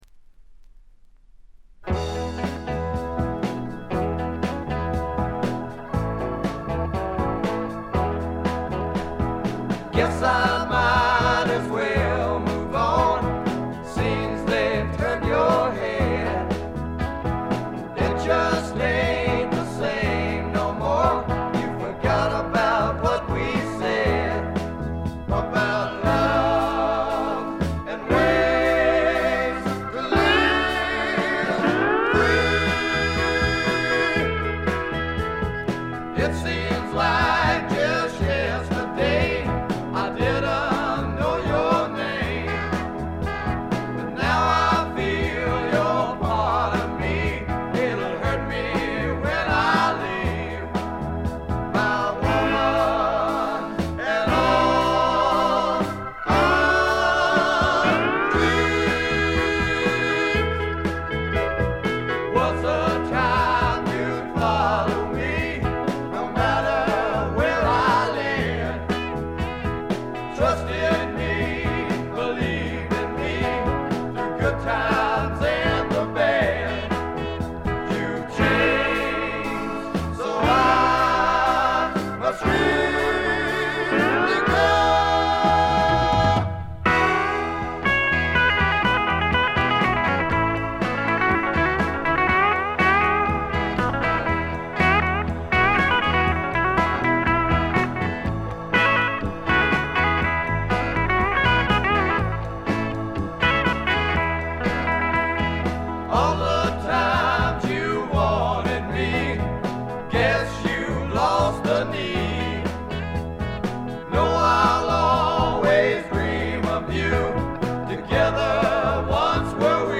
軽微なチリプチに散発的なプツ音少し。
試聴曲は現品からの取り込み音源です。